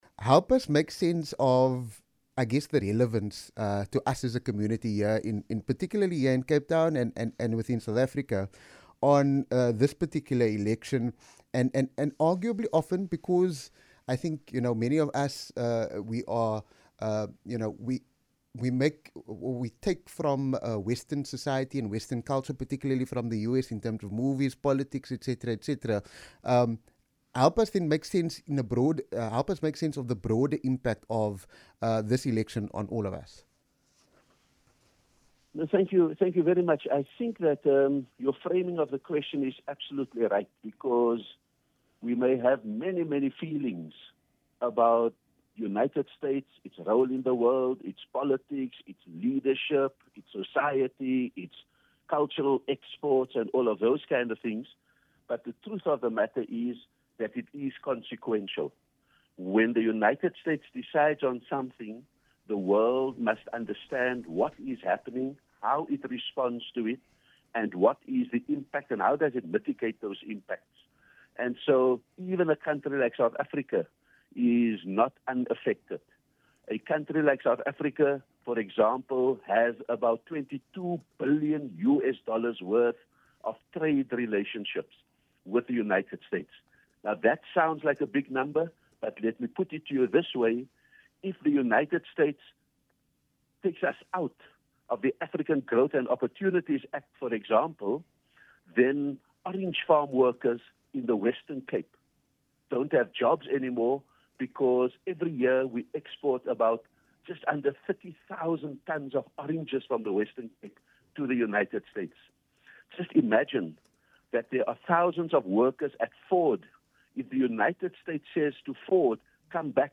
Speaking on VOC breakfast on Tuesday morning Ebrahim Rasool, the former South African Ambassador to the United States of America explains how the elections would affect SA.